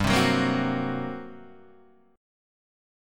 GmM7bb5 chord {3 3 4 3 1 2} chord